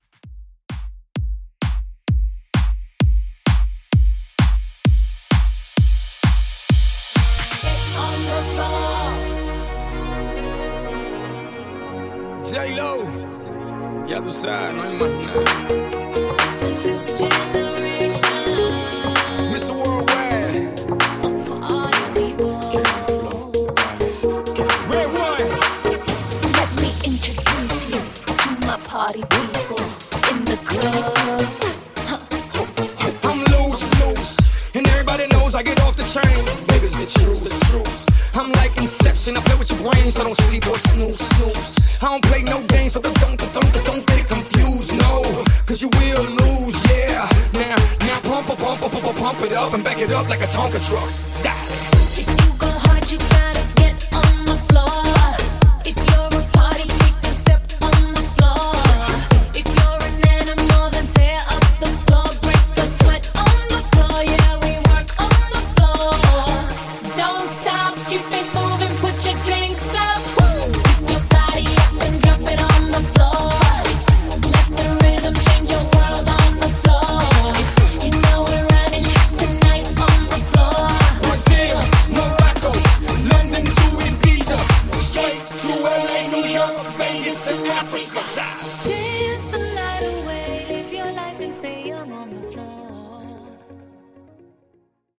Extended Dance)Date Added